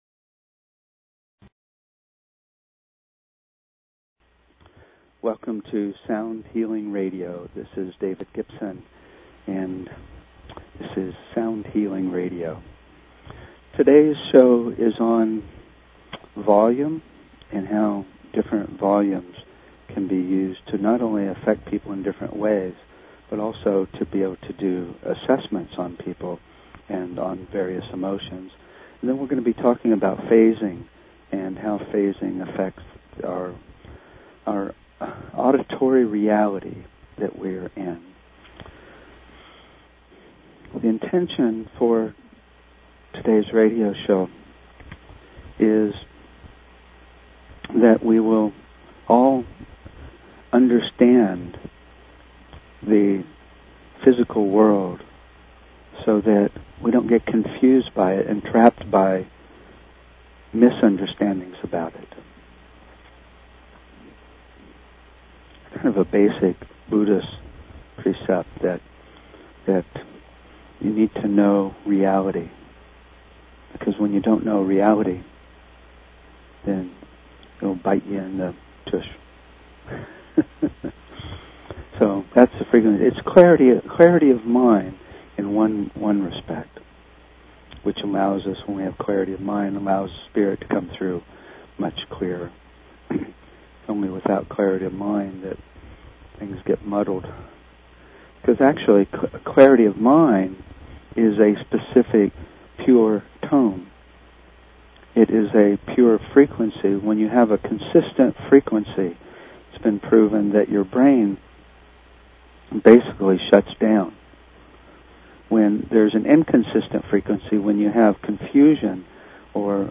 Talk Show Episode, Audio Podcast, Sound_Healing and Courtesy of BBS Radio on , show guests , about , categorized as
We will discuss and listen to examples of how volume changes carry various types of emotion. We will also discuss how important the phase of a sound is to our sense of reality, and how it can be used to show what is going on with a person physically, mentally, emotionally, and spiritually (of course, we'll explain what phase is).